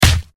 punch8.ogg